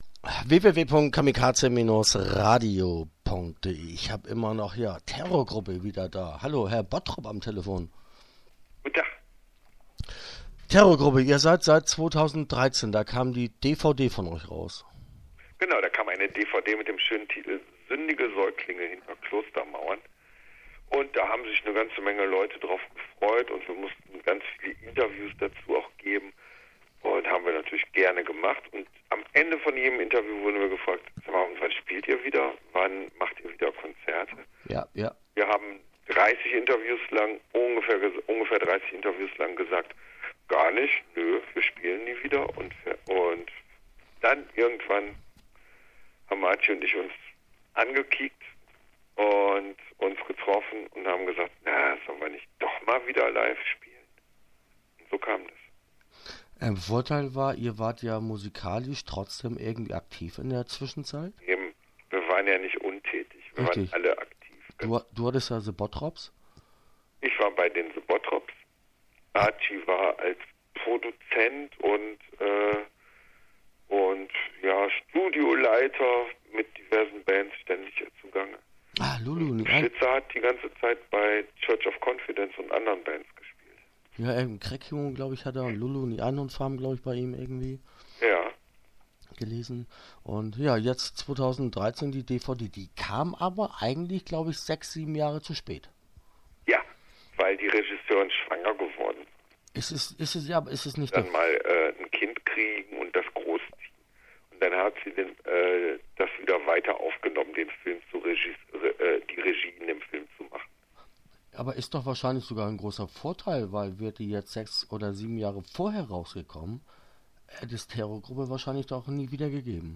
Interview Teil 1 (10:42)